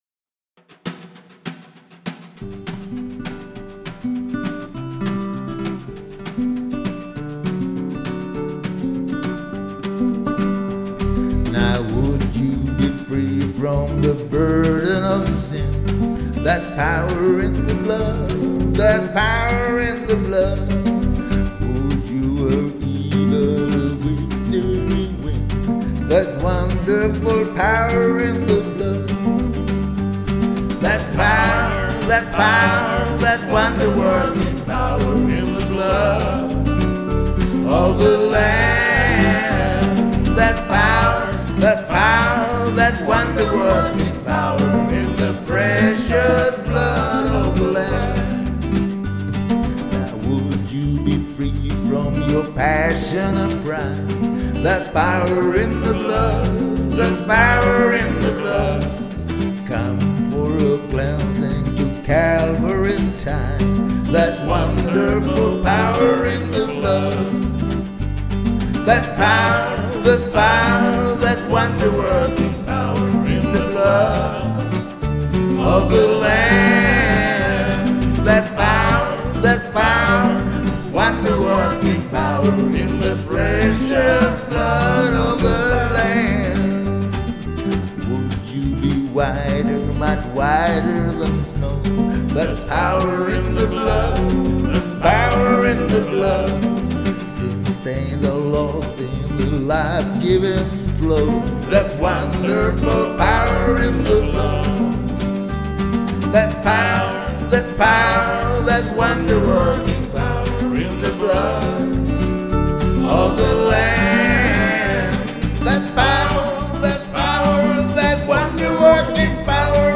akustisk gitarr, el-gitarr, mandolin
sång, akustisk gitarr, banjo, munspel
bas, chorus
trummor, chorus